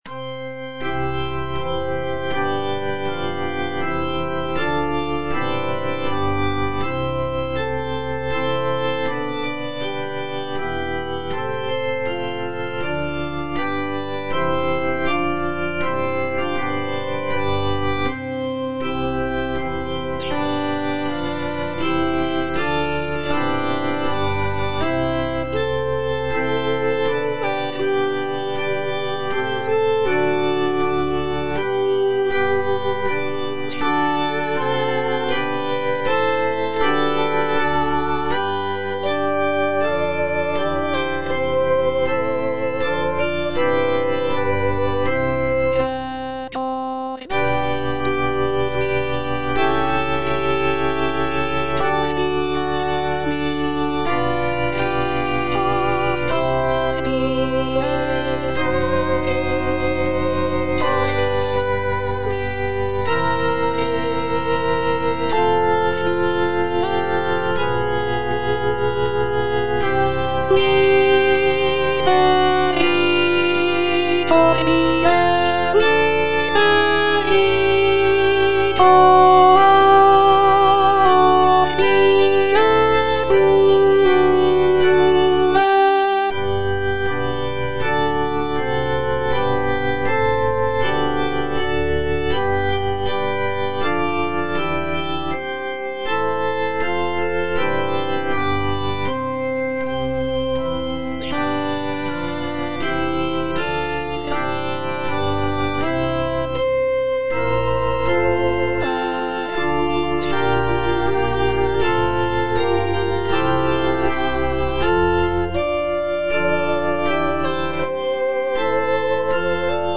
B. GALUPPI - MAGNIFICAT Suscepit Israel - Alto (Voice with metronome) Ads stop: auto-stop
The work features intricate vocal lines, rich harmonies, and dynamic contrasts, creating a powerful and moving musical experience for both performers and listeners.